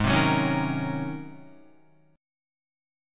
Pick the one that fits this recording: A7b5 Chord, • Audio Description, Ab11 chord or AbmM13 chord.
Ab11 chord